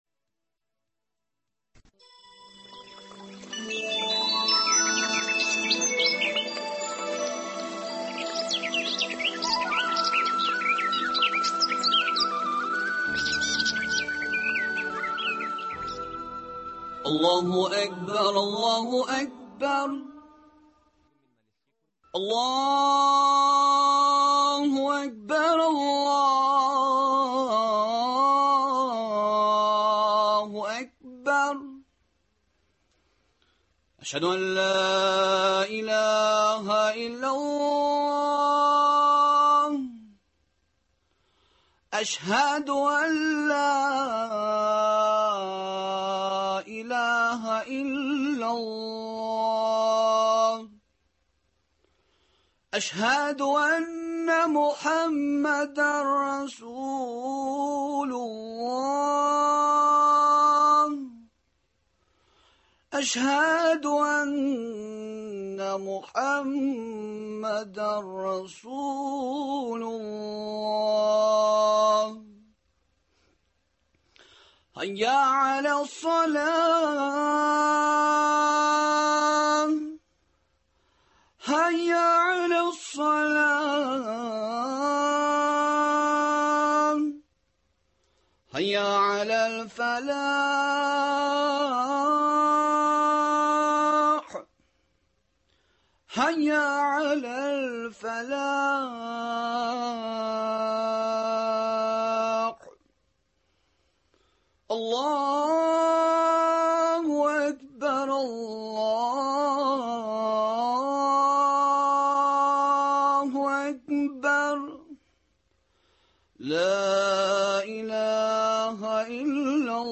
әңгәмәдән